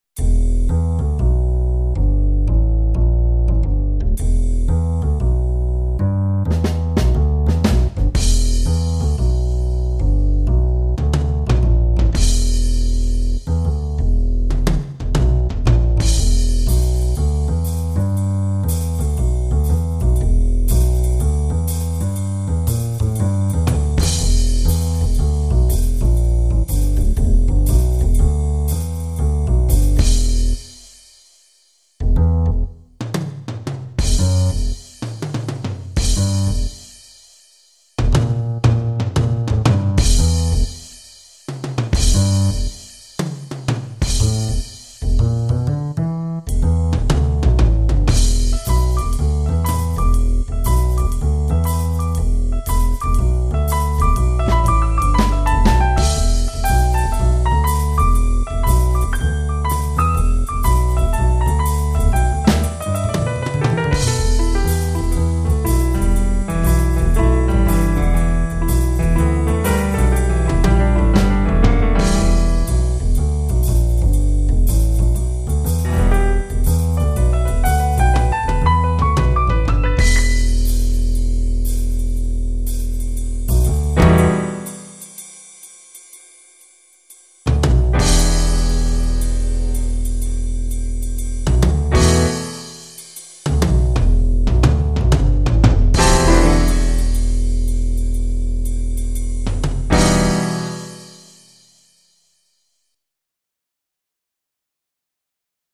instrumental collection